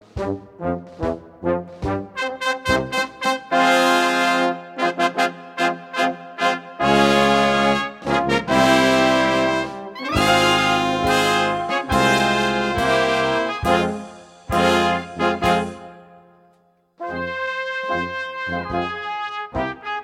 Interpret: dech. orchestr